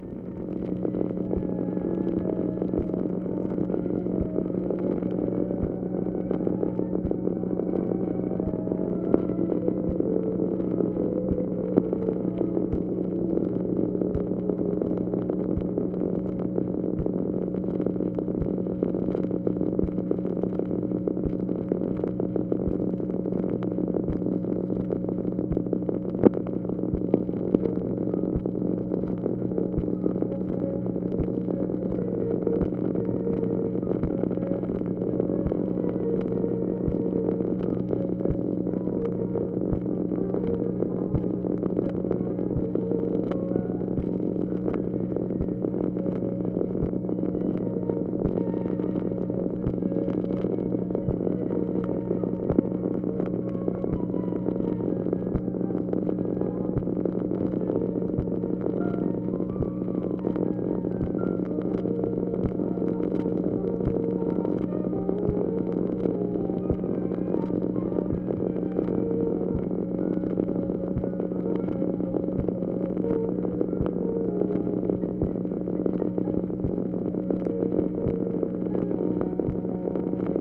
SOUNDS OF MUSIC PLAYING
OFFICE NOISE, February 26, 1965